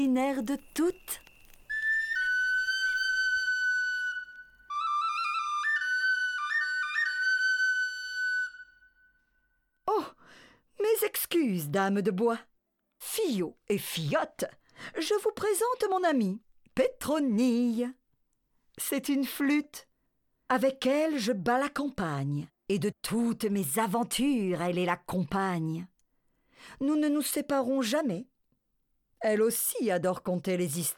Livre lu